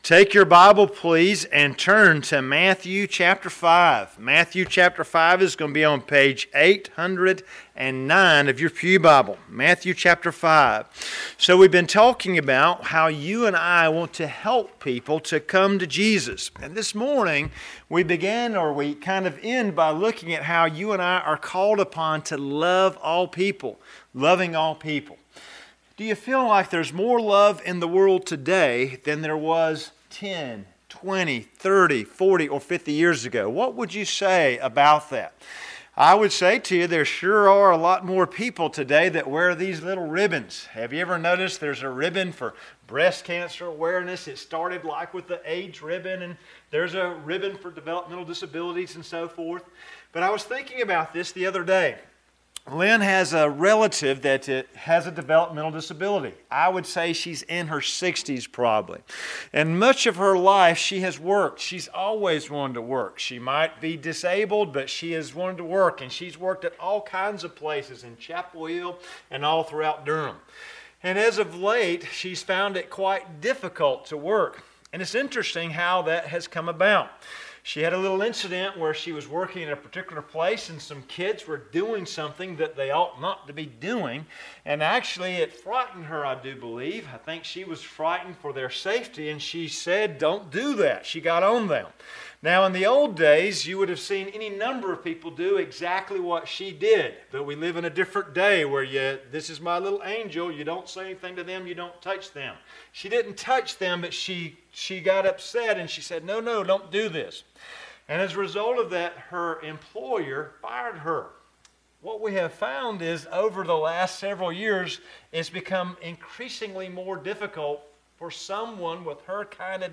Sermons - Tippett's Chapel Free Will Baptist Church
Sermon library of Tippett’s Chapel Free Will Baptist Church in Clayton, NC enables listeners to easily browse our Sunday morning sermons and worship services.